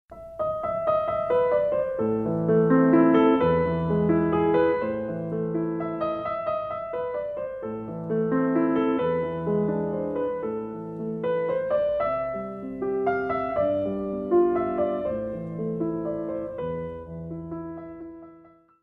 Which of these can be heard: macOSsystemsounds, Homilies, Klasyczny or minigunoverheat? Klasyczny